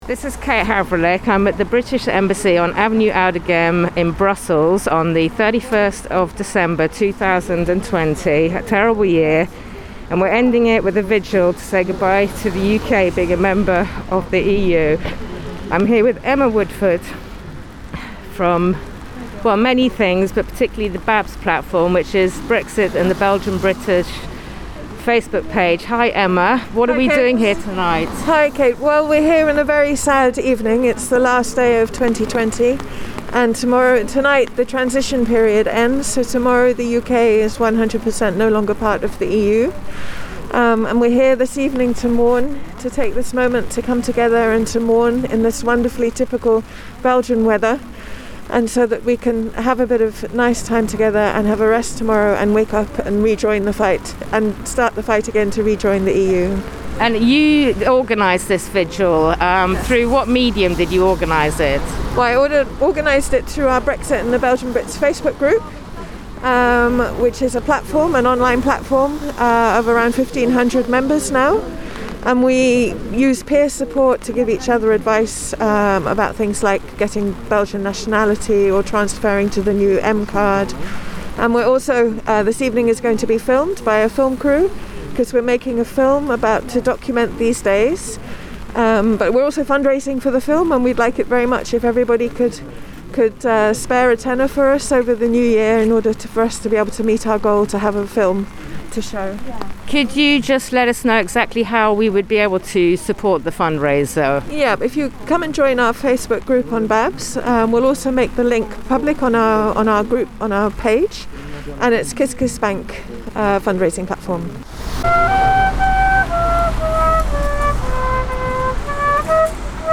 Several dozen Brits in Brussels braved the cold and rain for a candelit vigil on New Year's Eve to mark the end of the Brexit transition period. Wearing EU-flag facemasks, the group gathered opposite the British Embassy on Avenue d'Auderghem for speeches, a minute's silence and renditions of Ode to Joy and Auld Lang Syne.